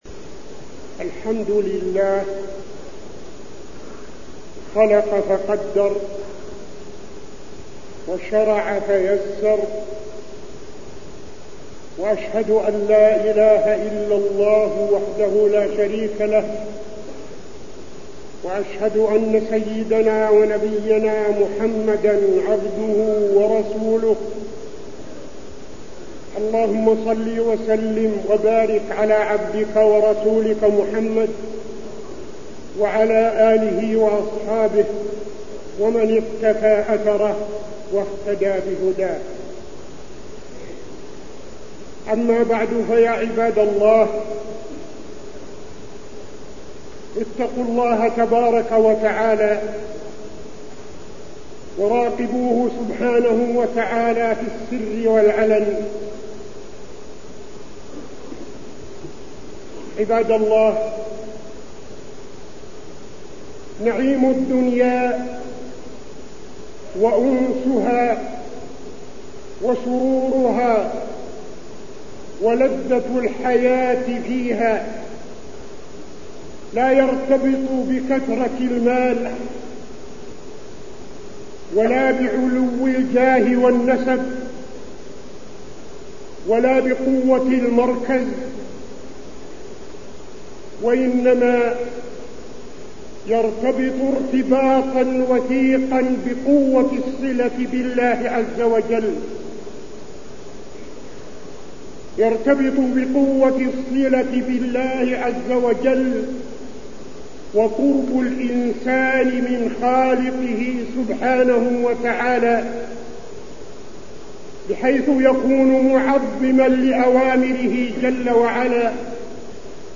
خطبة خشية الله تعالى وفيها: نعيم الدنيا مرتبط بقوة الصلة بالله تعالى، ومراقبة النفس ومحاسبتها، والبعد عن الغفلة
تاريخ النشر ١٦ رجب ١٤٠٣ المكان: المسجد النبوي الشيخ: فضيلة الشيخ عبدالعزيز بن صالح فضيلة الشيخ عبدالعزيز بن صالح خشية الله تعالى